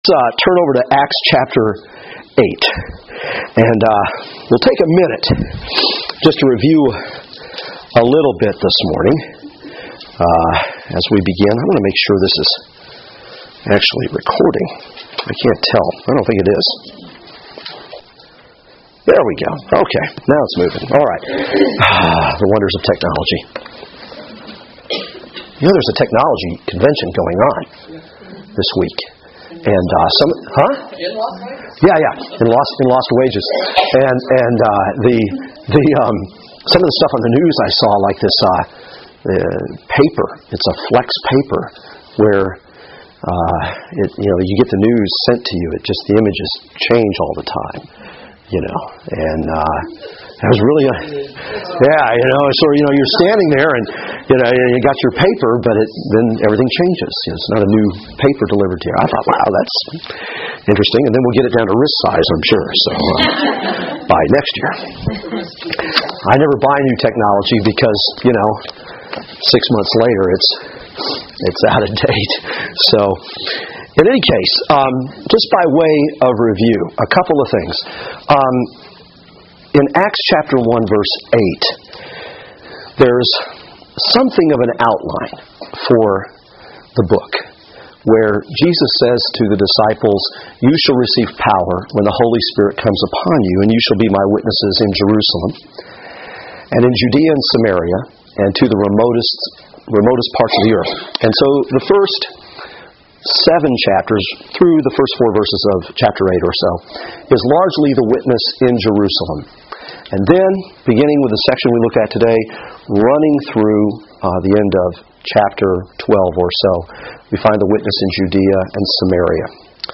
Passage: Acts 8-12 Service Type: Women's Bible Study « The Hinge of Mission The Witness in Judea and Samaria